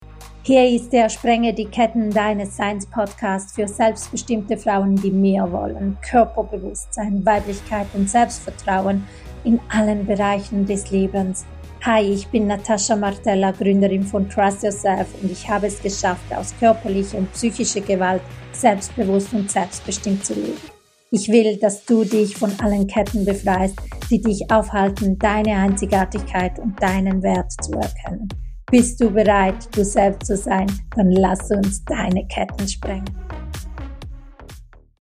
Trailer - Sprenge die Ketten deines Seins!
Ich spreche hier frei, ohne Script und direkt aus